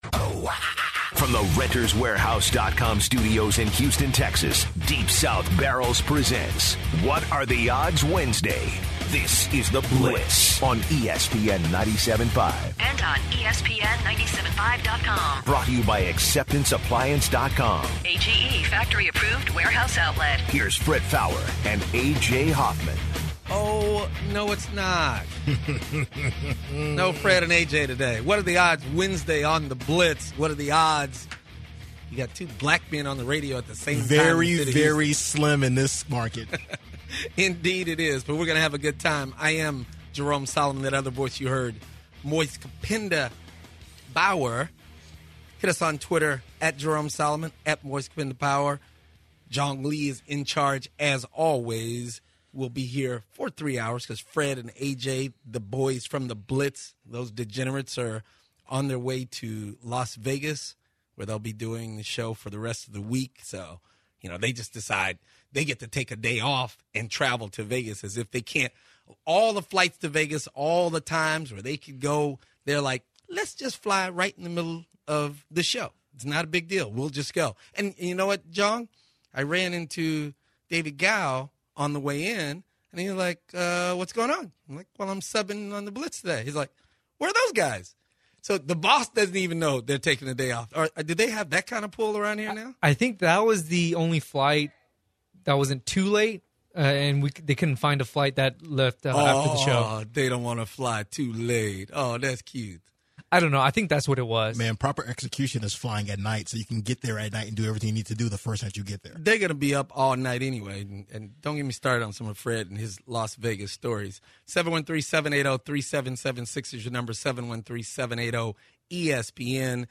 They talked about the Patriots fans’ and their reactions to the deflate gate and how it will affect their draft. The guys spoke with Chris Broussard, ESPN reporter, about the NBA.